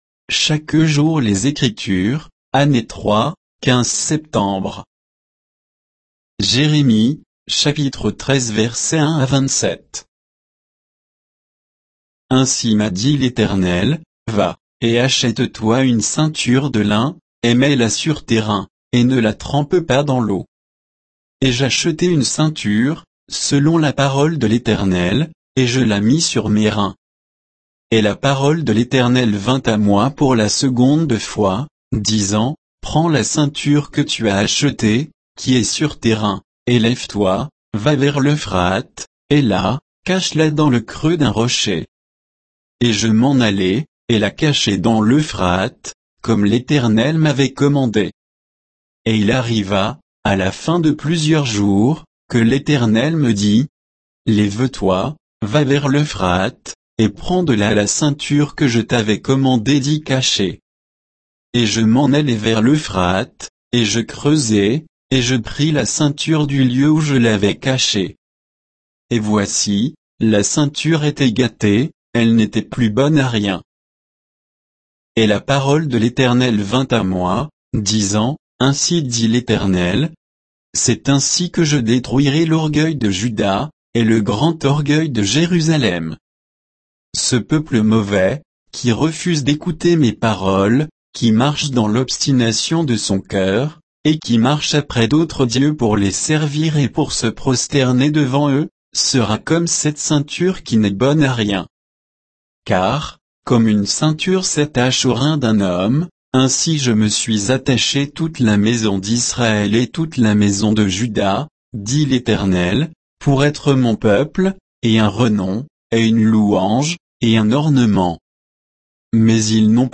Méditation quoditienne de Chaque jour les Écritures sur Jérémie 13, 1 à 27